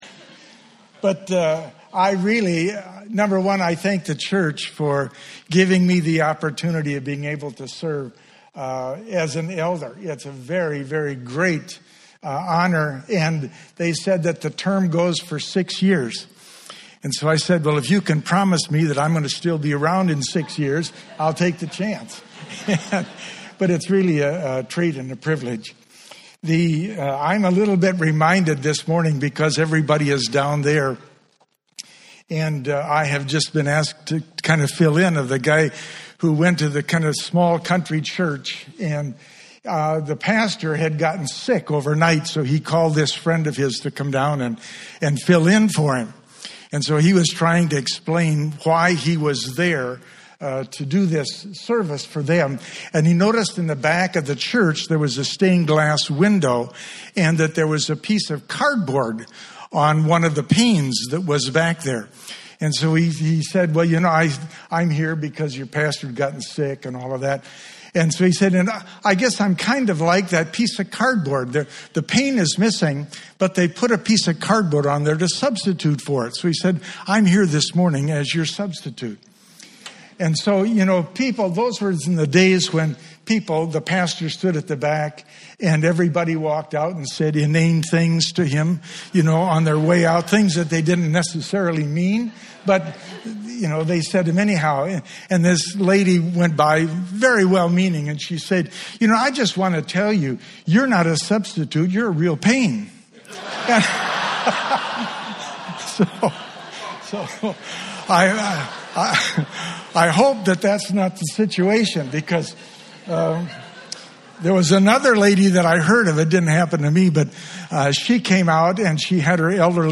Bible Text: 2 Peter 1:3-11 | Speaker: Guest Preacher | Series: General…